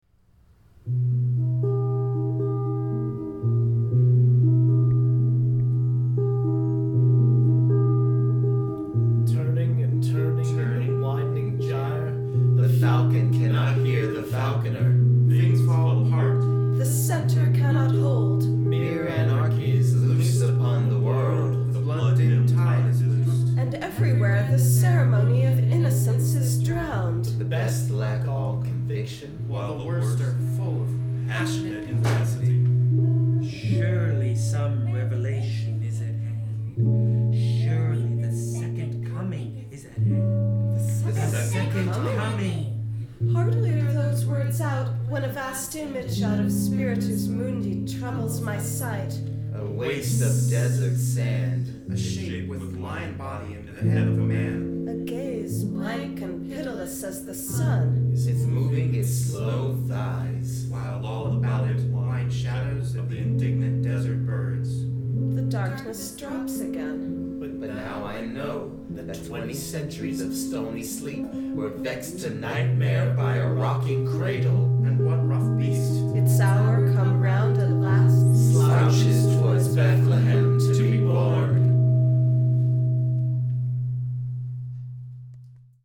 Melodic, guitar-driven, unraveling.
Recorded in an open session at the Baltimore Free School, 1323 N. Calvert, on Saturday, December 4, from 2-3:30 pm.